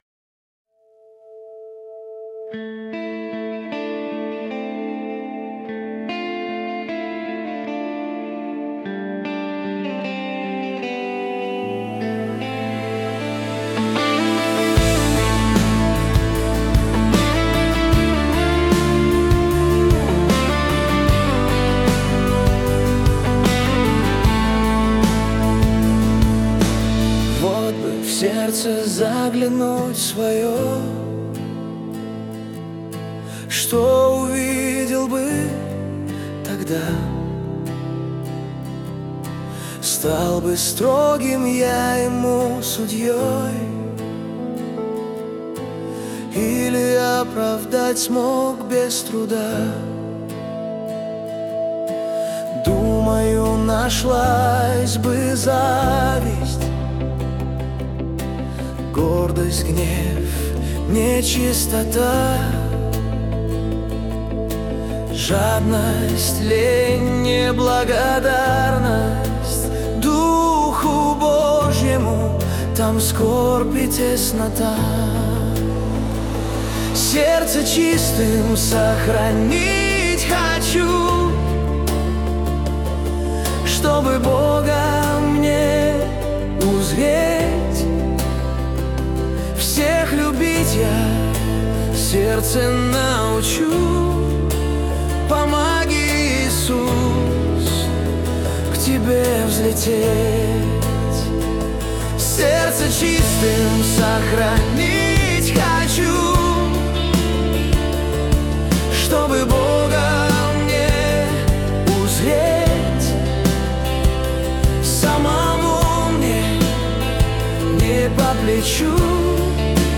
песня ai
266 просмотров 671 прослушиваний 102 скачивания BPM: 76